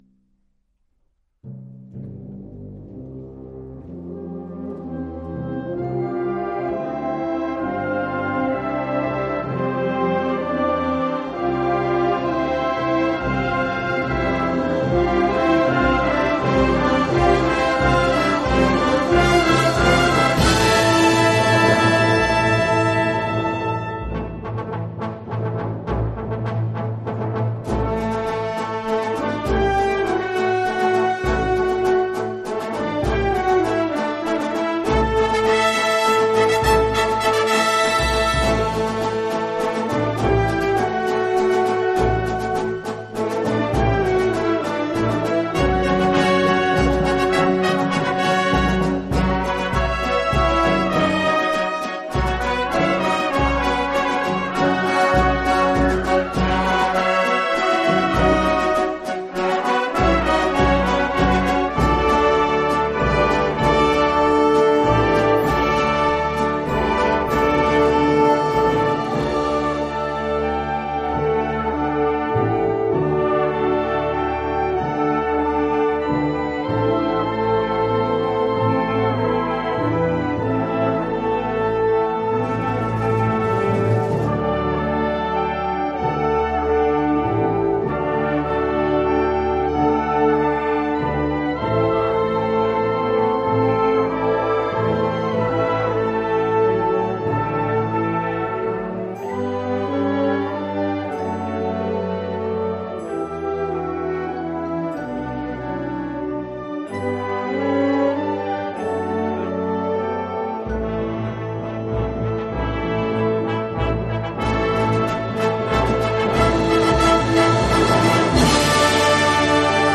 Genre musical : Classique
Oeuvre pour orchestre d’harmonie.